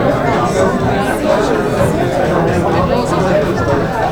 bar crowd loop 02.wav